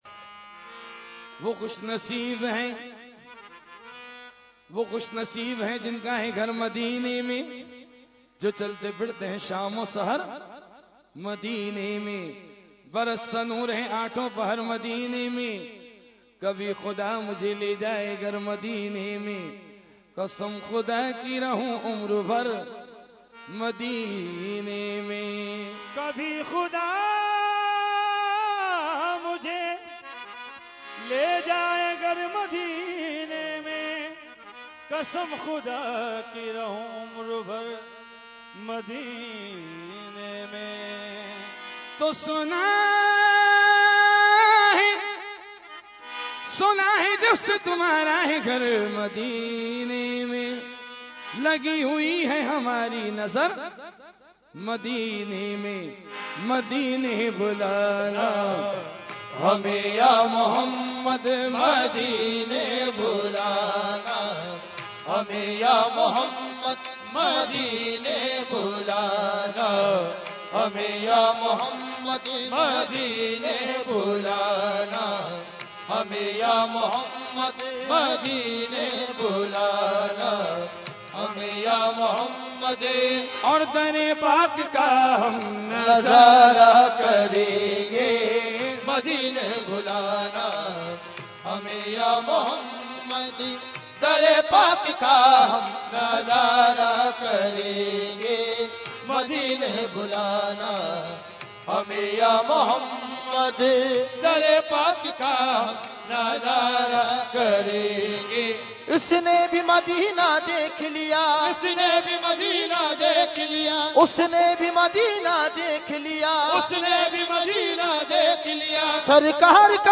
Category : Qawali | Language : UrduEvent : Urs Qutbe Rabbani 2016